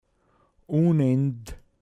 pinzgauer mundart
U(n)end, m. Ungeduld